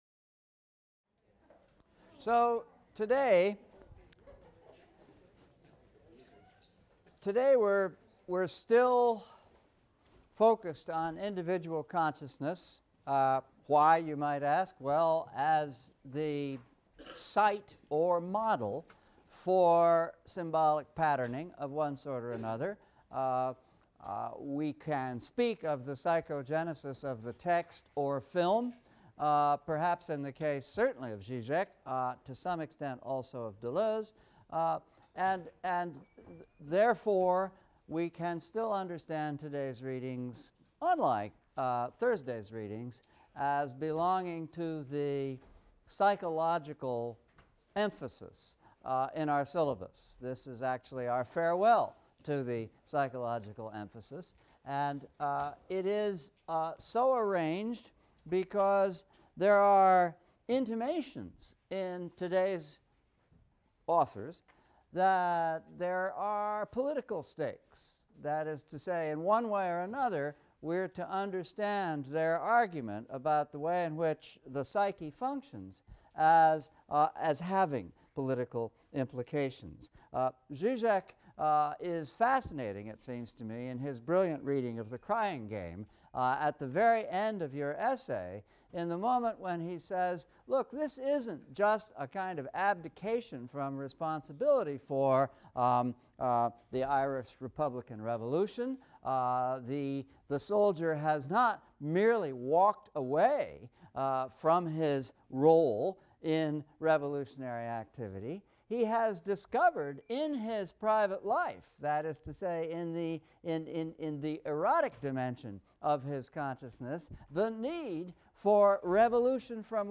ENGL 300 - Lecture 15 - The Postmodern Psyche | Open Yale Courses